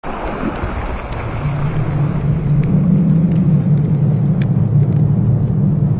• wind howling 5 .ogg
[wind-howling]_(5)_pfb.wav